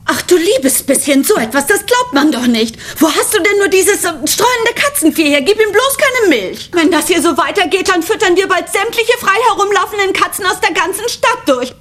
Synchronort: Hamburg